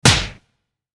slap.mp3